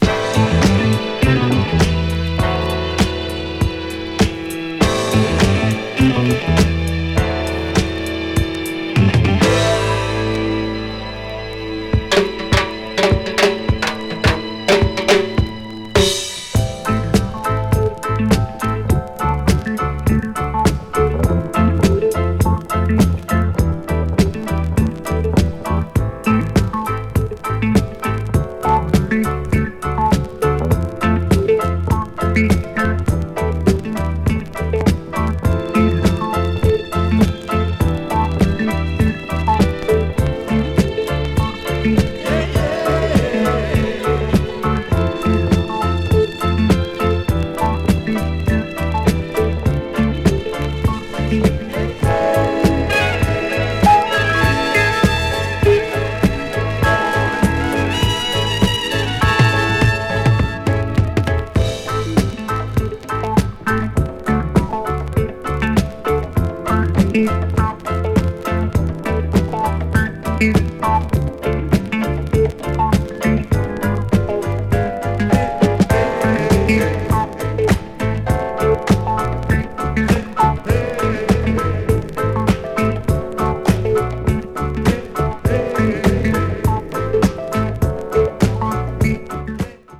ジャマイカのバンドながらUKレゲエ的なサウンドが特徴です！
70s FUNKY REGGAE / DUB 踊る 詳細を表示する